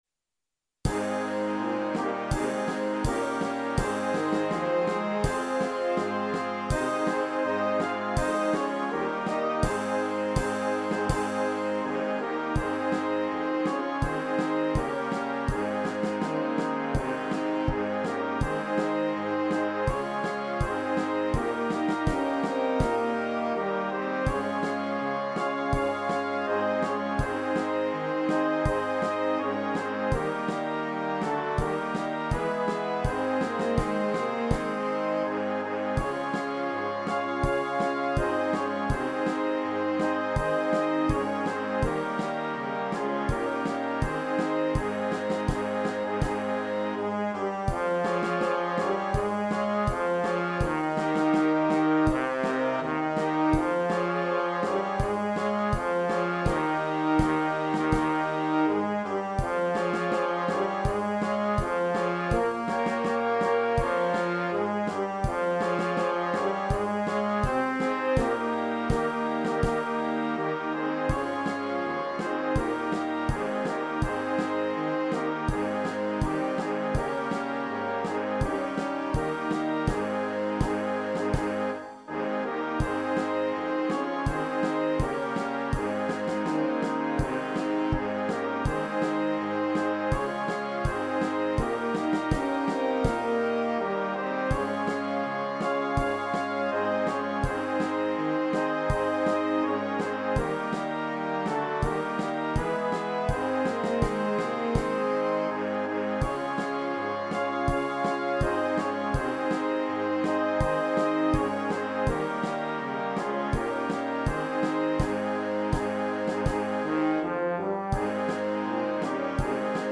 Prozessionsmarsch